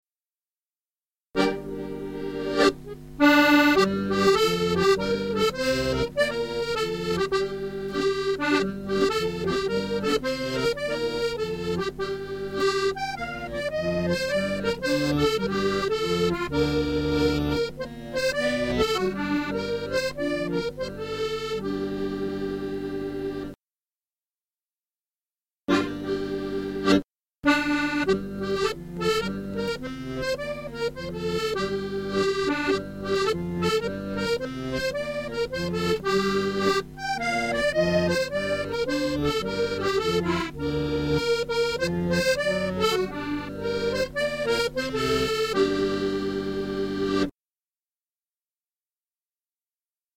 Maypole Dancing Guide